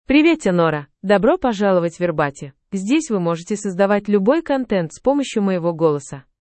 Nora — Female Russian AI voice
Nora is a female AI voice for Russian (Russia).
Voice sample
Listen to Nora's female Russian voice.
Female